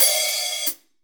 12HH PU2.wav